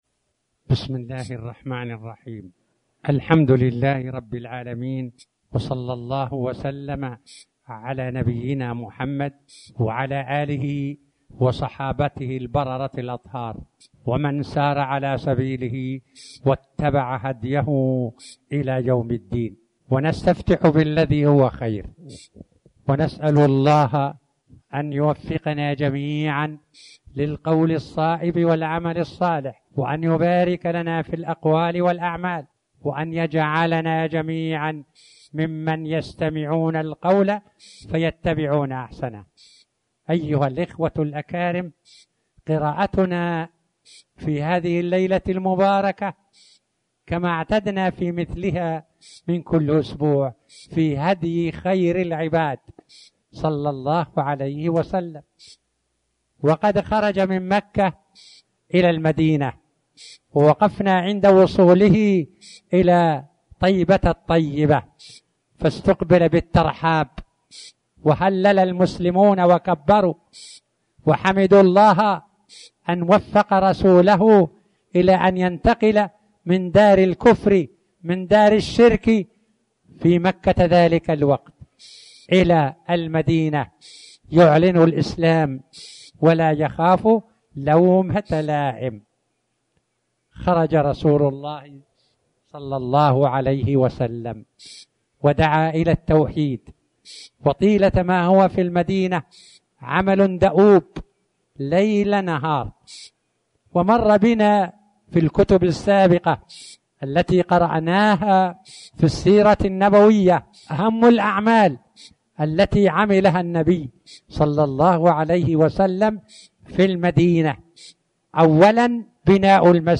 تاريخ النشر ٢٦ شوال ١٤٣٩ هـ المكان: المسجد الحرام الشيخ